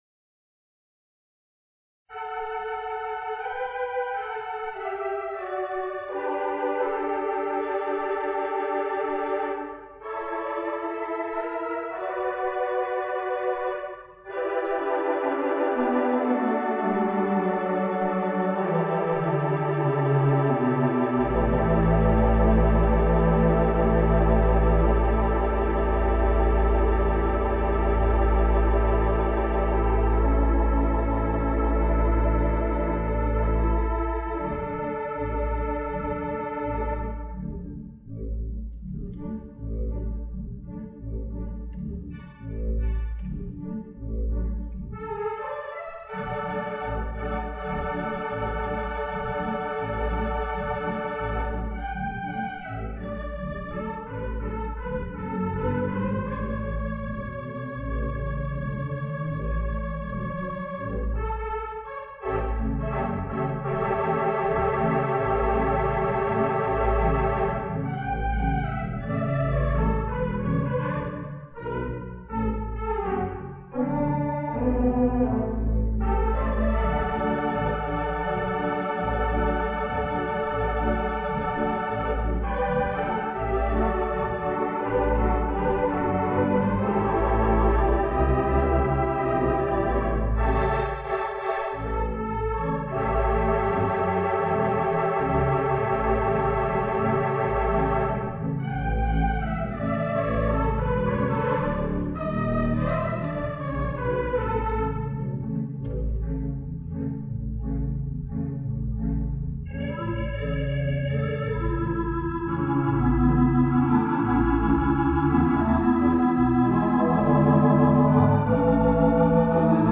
Palace Theatre in Canton, Ohio.
This Theatre Pipe Organ has three manuals and eleven ranks.
The instrument is comfortable to sit at and play, and the sound is powerful and symphonic in the atmospheric auditorium where it lives.
The 3/11 Mighty Kilgen Theatre Pipe Organ installed at the Palace Theatre sounds like a symphony orchestra with a classical flare.
Ohio during the Ohio Organ Crawl.